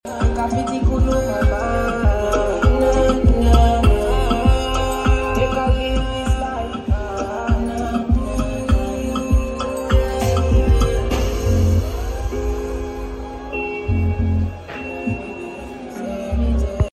5.1soundsystem which has a higher bass and available worldwide